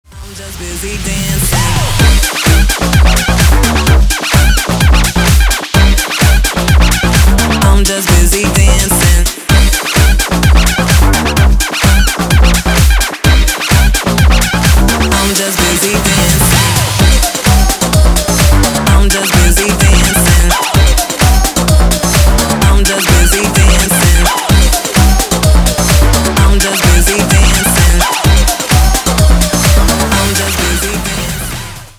• Качество: 320, Stereo
house
electro house